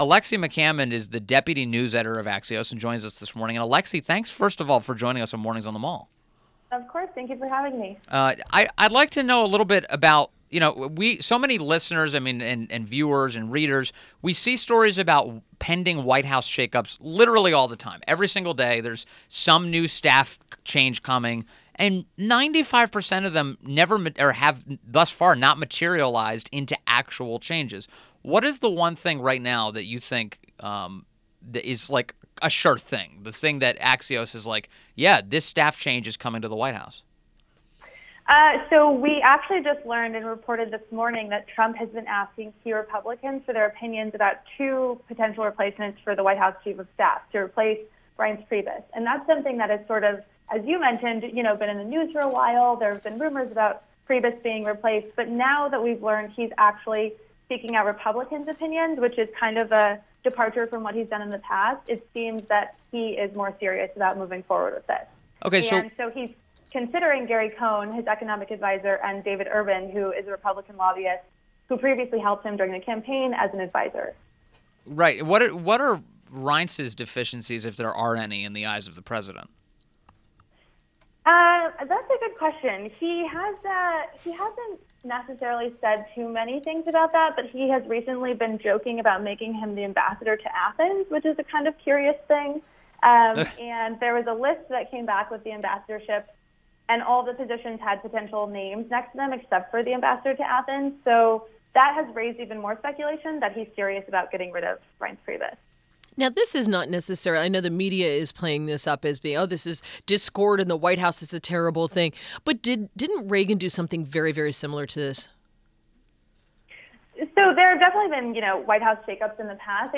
WMAL Interview - ALEXI MCCAMMOND 05.31.17